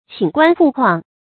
寢關曝纊 注音： ㄑㄧㄣˇ ㄍㄨㄢ ㄆㄨˋ ㄎㄨㄤˋ 讀音讀法： 意思解釋： 人睡在關隘之上，蠶繭曬在日光之下。比喻不得安寧。